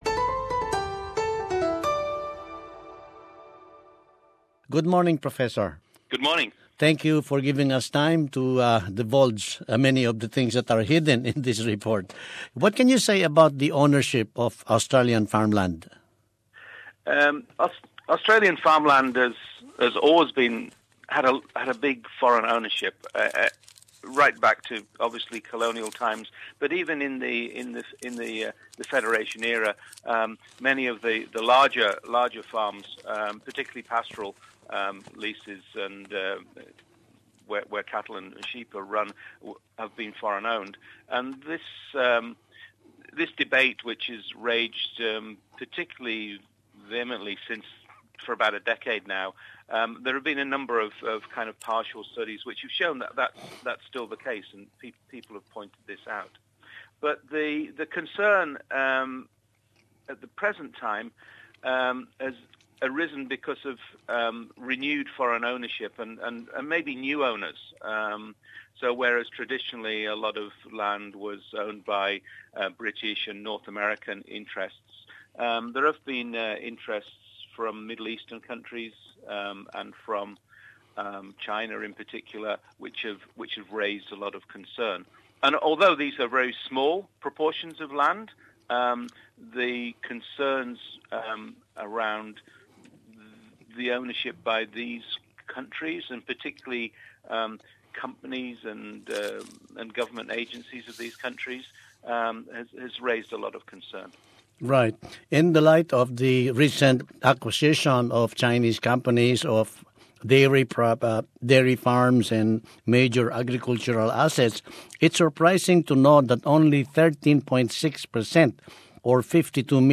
Kinapayam